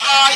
Vox (Aight).wav